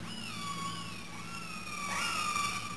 1 channel
whale.wav